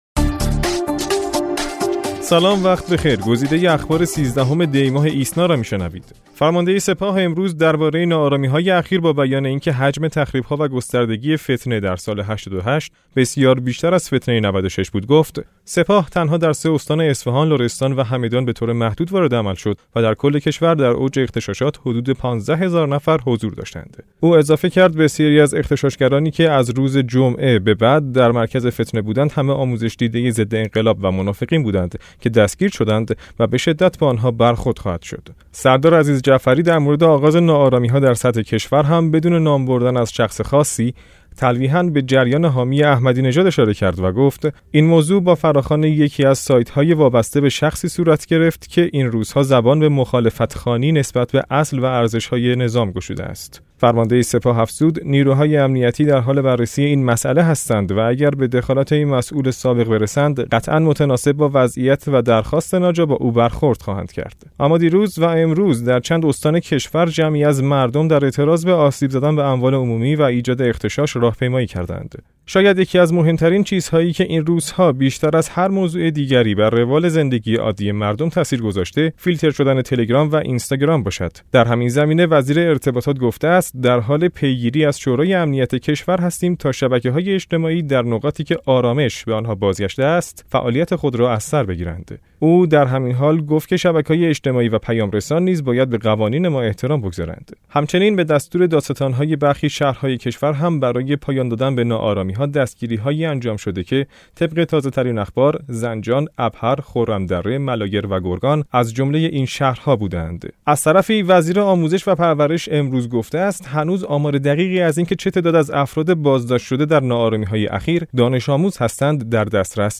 صوت / بسته خبری ۱۳ دی ۹۶